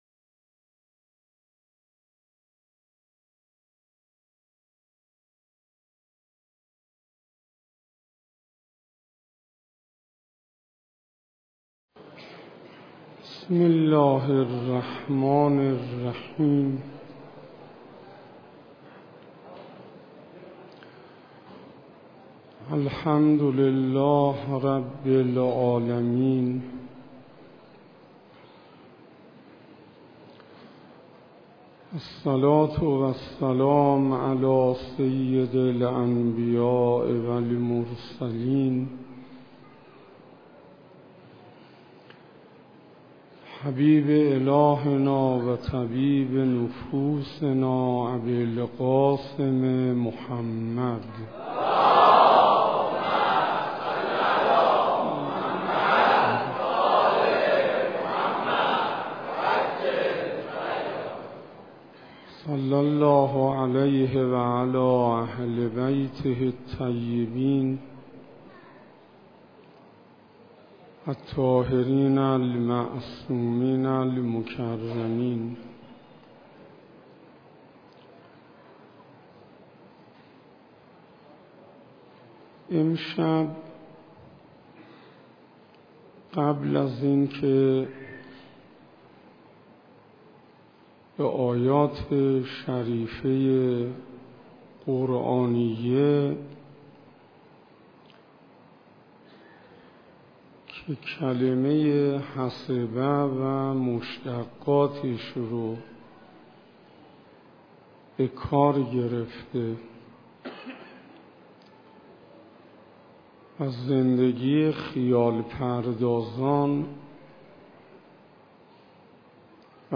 سخنرانی حجت الاسلام انصاریان